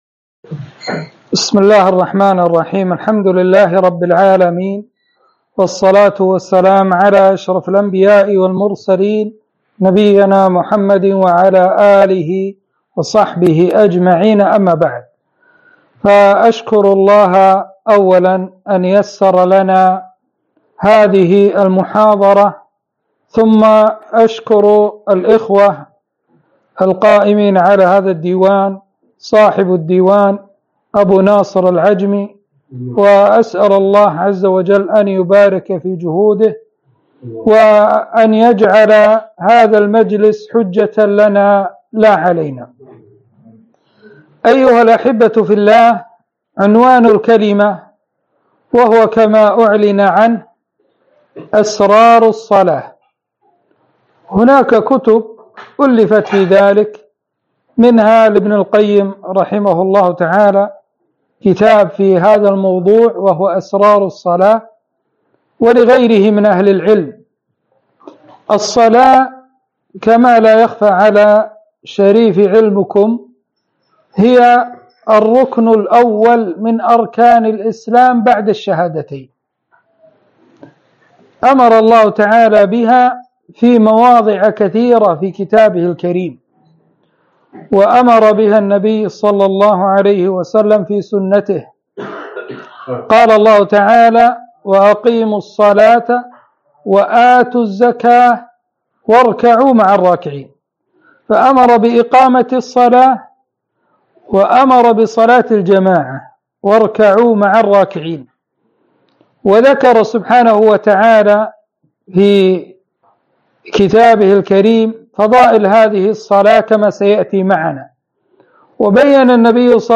محاضرة - أسرار الصلاة